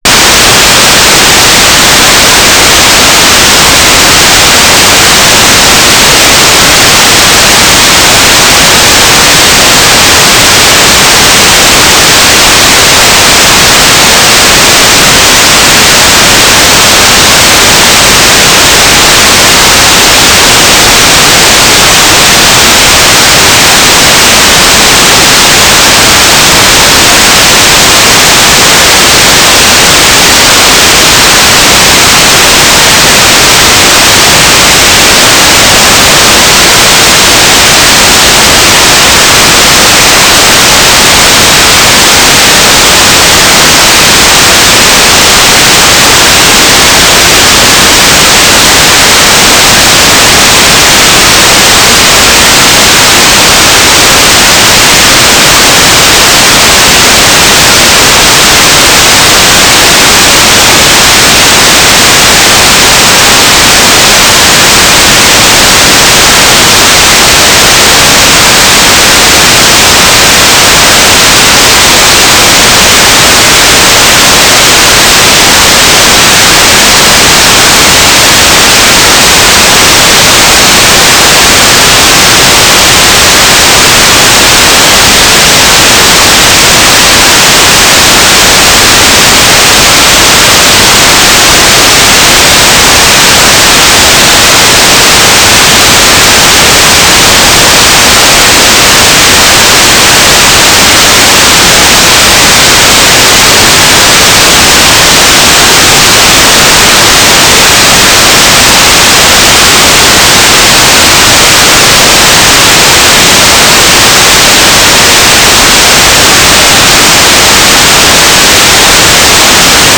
"transmitter_description": "Mode U - Transmitter",